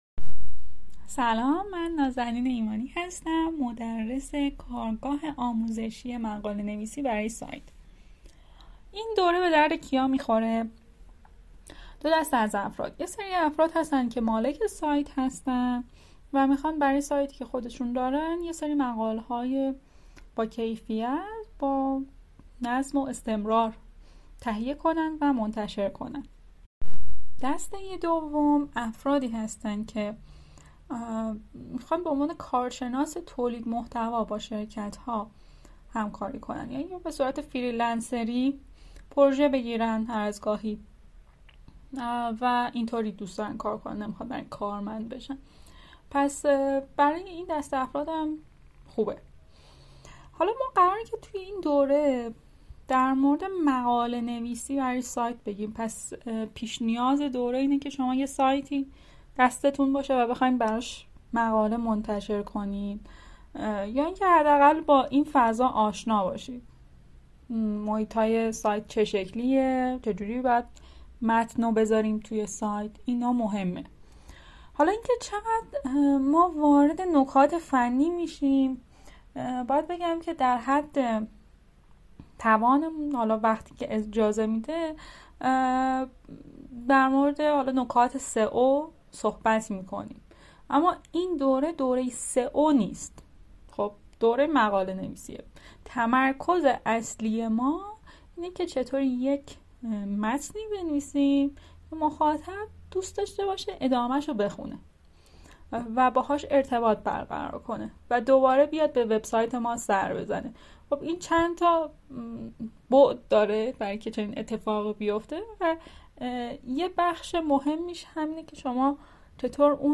🔊 معرفی صوتی کارگاه